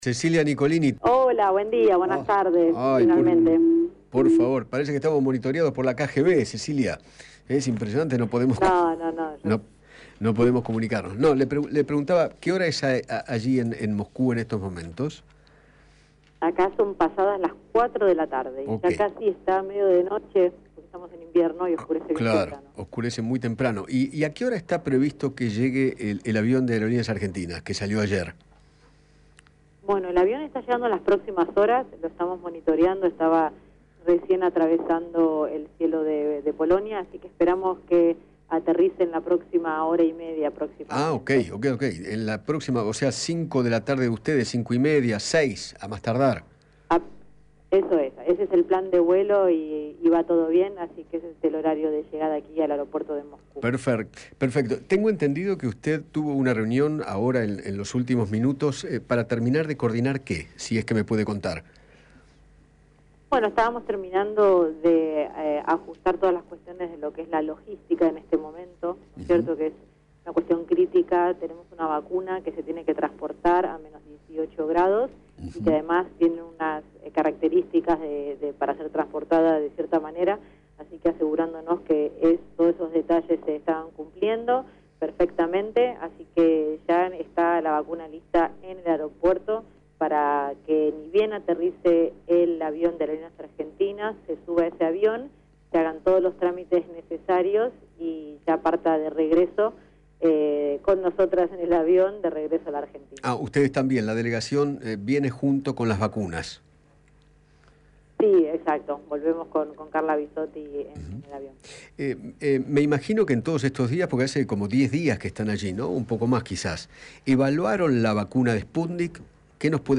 Cecilia Nicolini, asesora presidencial y miembro de la comitiva oficial en Moscú, conversó con Eduardo Feinmann sobre la efectividad de la vacuna rusa y el arribo de la misma a la Argentina.